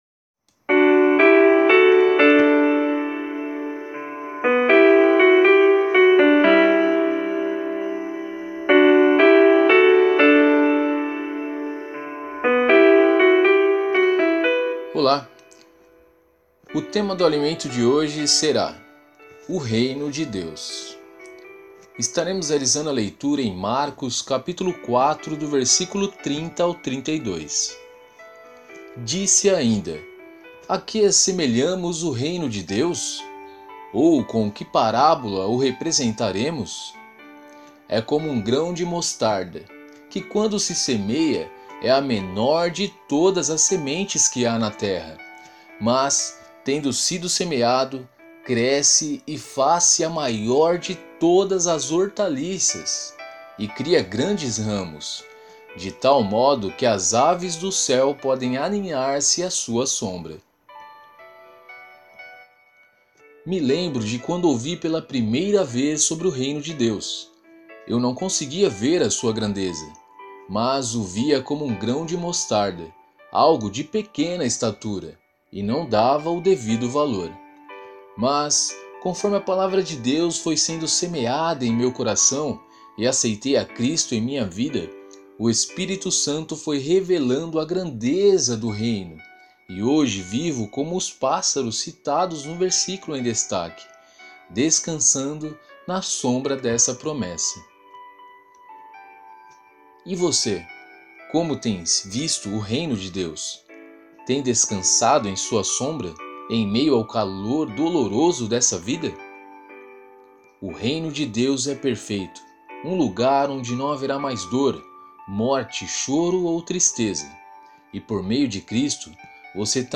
Você também pode ouvir a narração do Alimento Diário!